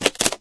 mrp_reload_01.ogg